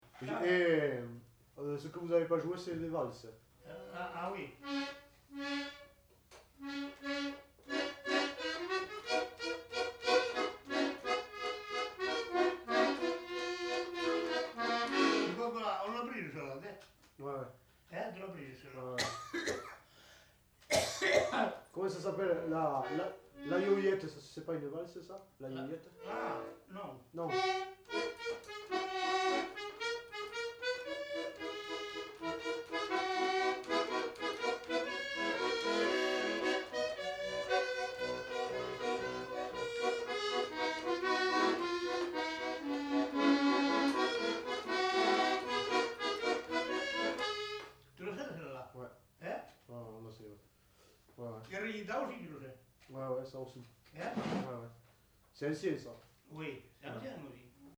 Aire culturelle : Lauragais
Lieu : Gardouch
Genre : morceau instrumental
Instrument de musique : accordéon diatonique